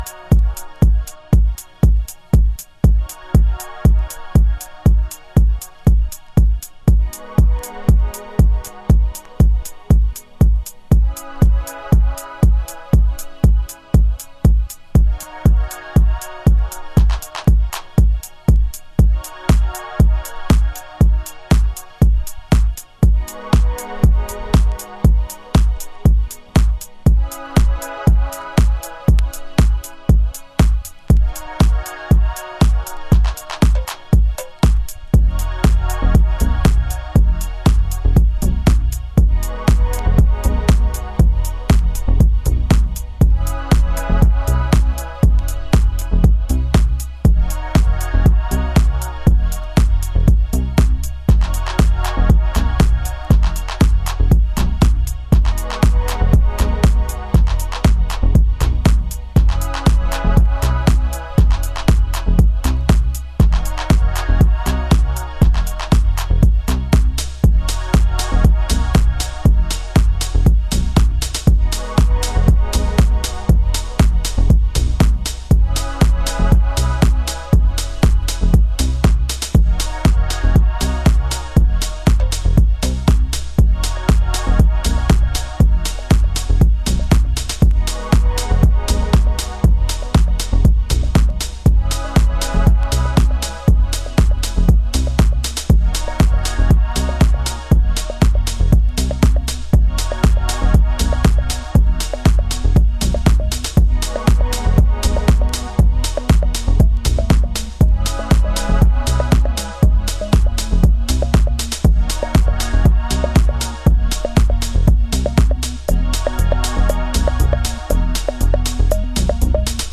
エクスタティックな空間系シンセが揺らめく4トラック。